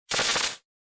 electro1.wav